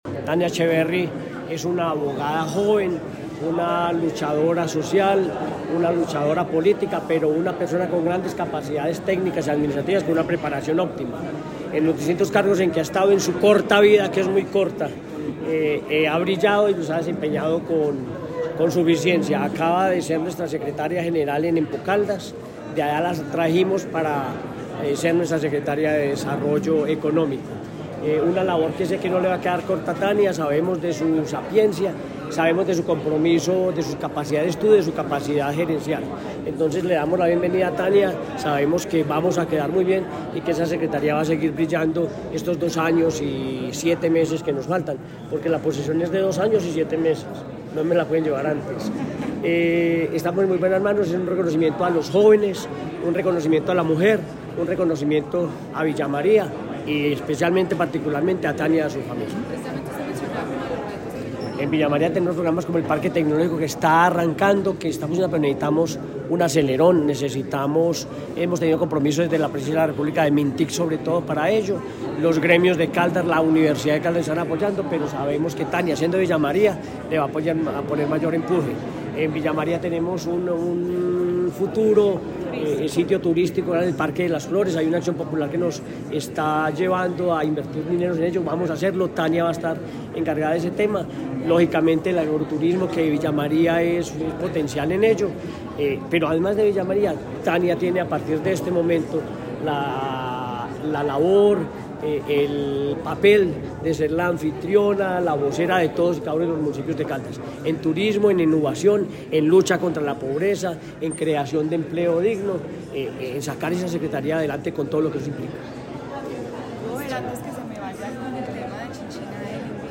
Henry Gutiérrez Ángel, gobernador de Caldas
Henry-Gutierrez-Gobernador-de-Caldasmp3.mp3